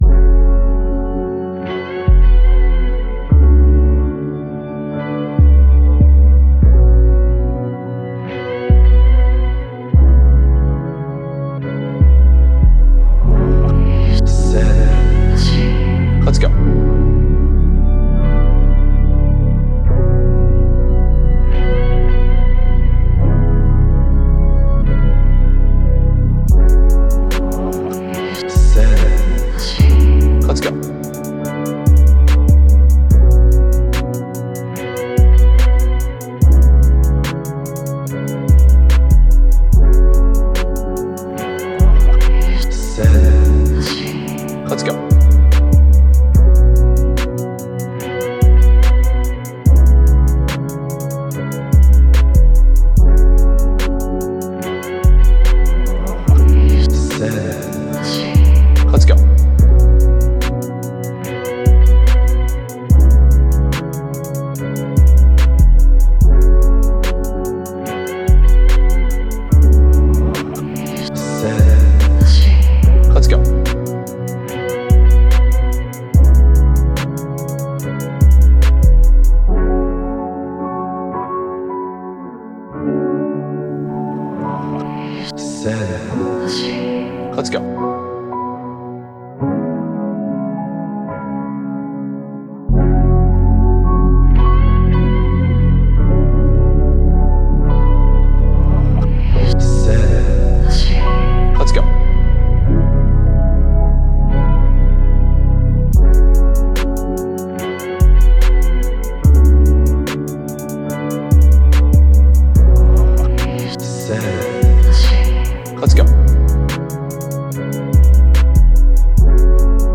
R&B – Lovely – Trapsoul – Type Beat
Key: Fm
145 BPM